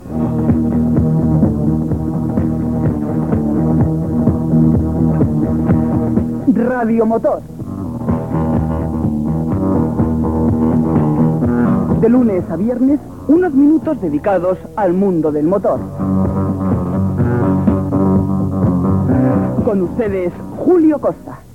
Careta del programa